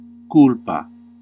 Ääntäminen
Synonyymit erreur forfait doublon faillance Ääntäminen France: IPA: [fot] Haettu sana löytyi näillä lähdekielillä: ranska Käännös Ääninäyte 1. culpa {f} 2. error {m} Suku: f .